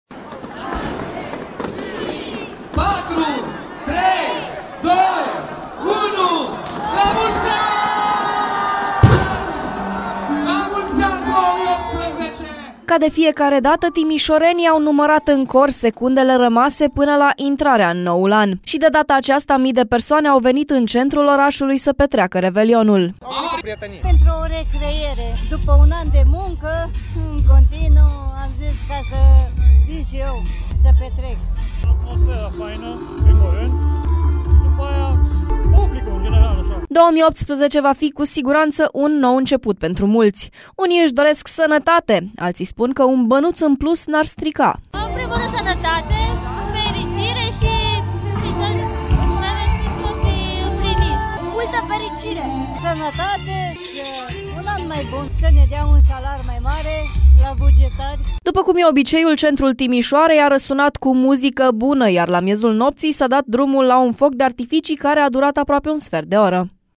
Mii de timişoreni au ales să treacă în noul an în centrul oraşului, după modelul marilor oraşe ale lumii.